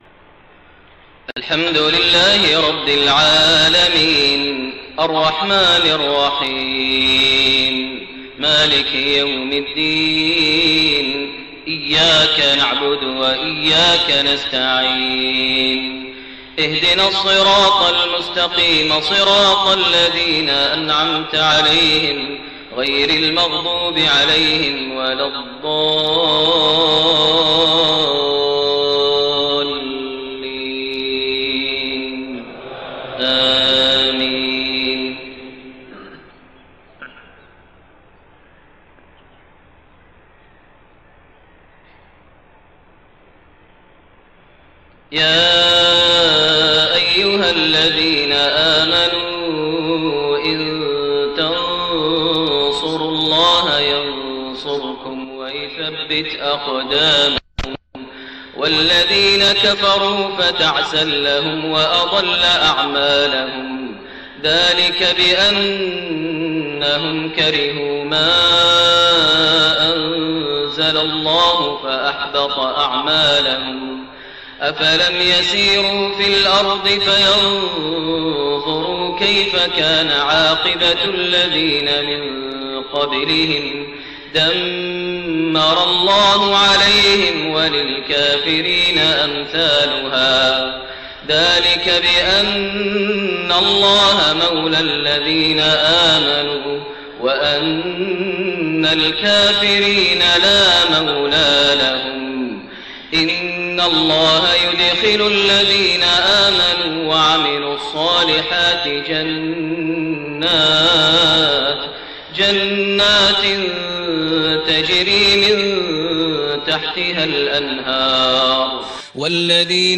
Isha prayer from Surat Muhammad > 1429 H > Prayers - Maher Almuaiqly Recitations